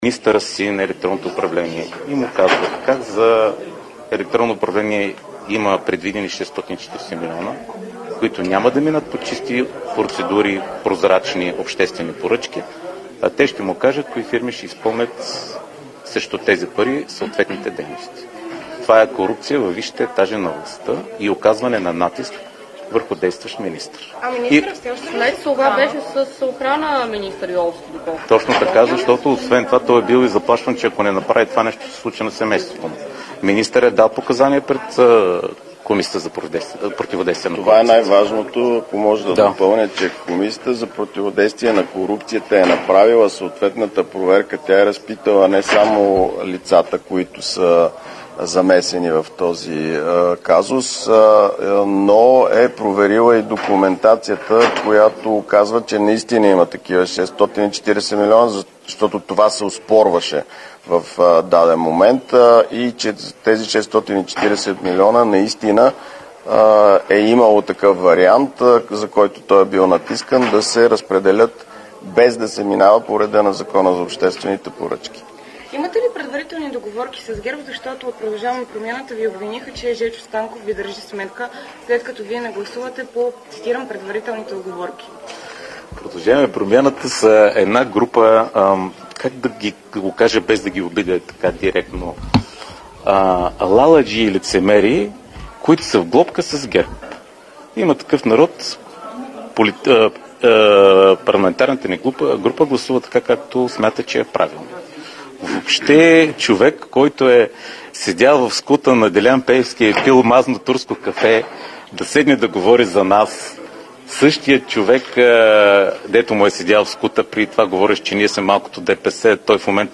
11.15 - Брифинг на председателя на групата на БСП за България в СОС Иван Таков за предстоящото гласуване на комисиите.
Директно от мястото на събитието